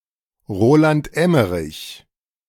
Roland Emmerich (German: [ˈʁoːlant ˈɛməʁɪç]